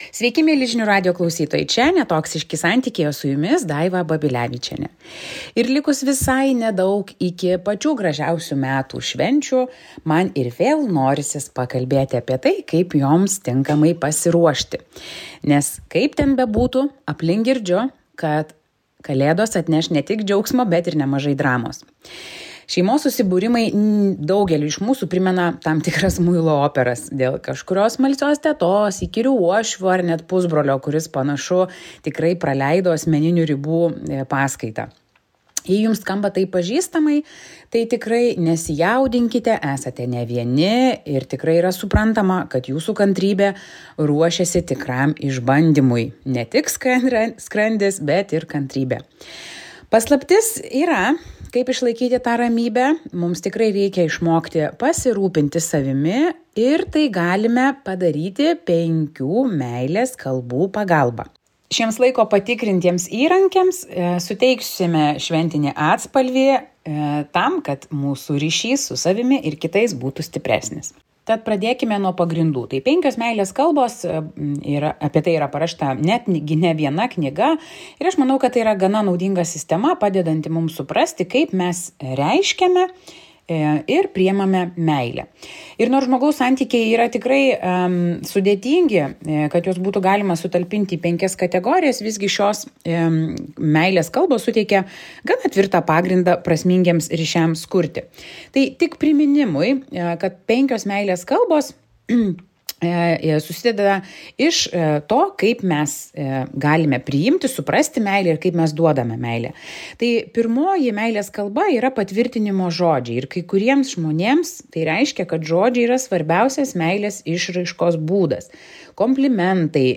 Šios dienos komentaras būtent apie tai – kaip galime panaudoti 5 meilės kalbas, idant šventės sujungtų, o ne atskirtų.